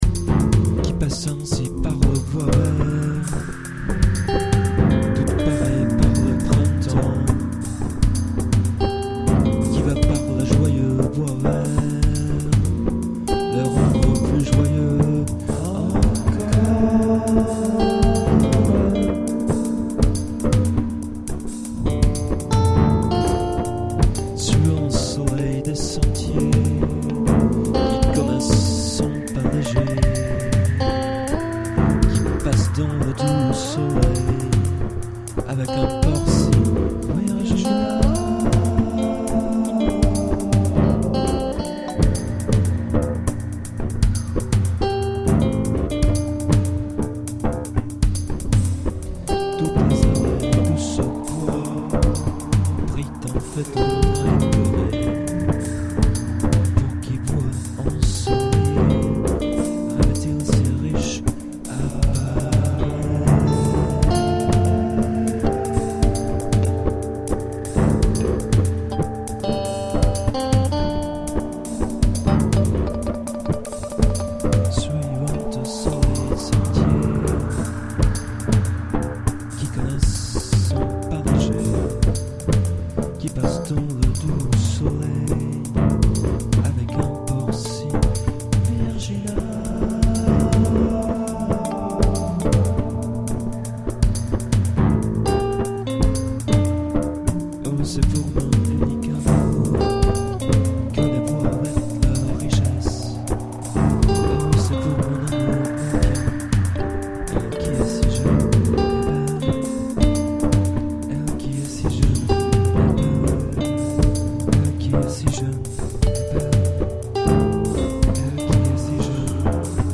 alternatív, experimentális, avantgarde, underground